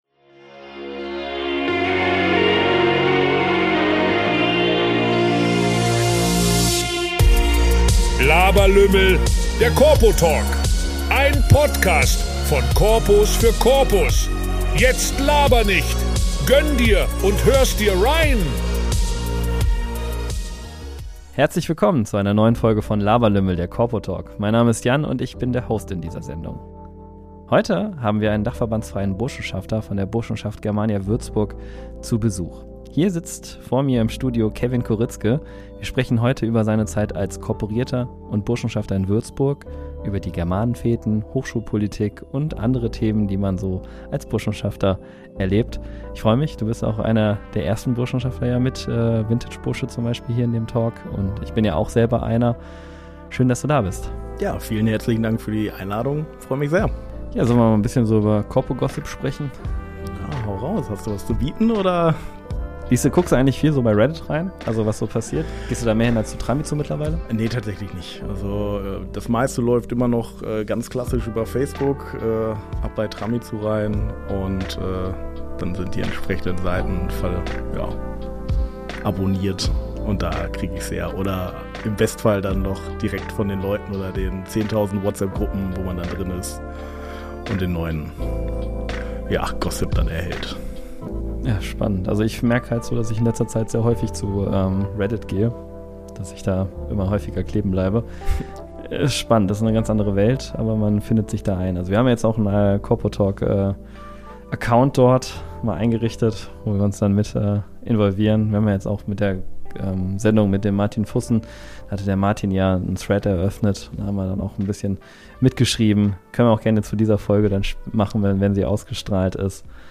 Hier sitzt vor mir im Studio